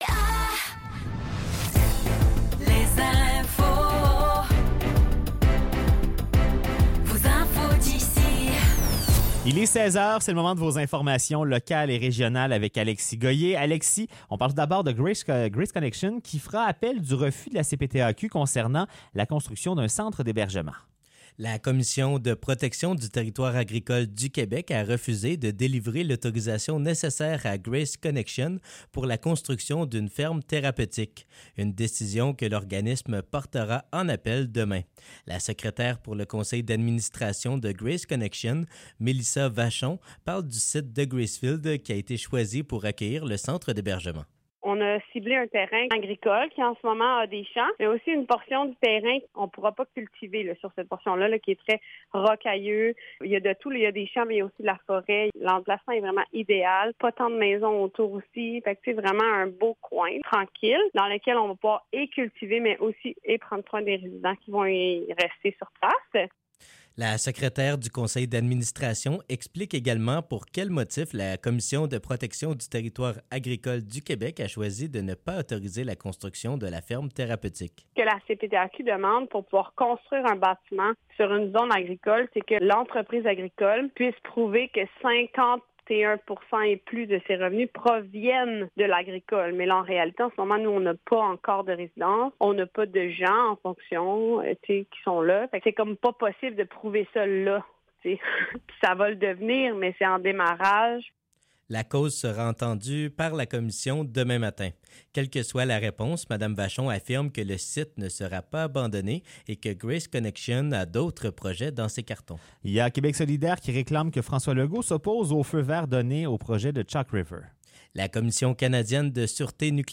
Nouvelles locales - 24 janvier 2024 - 16 h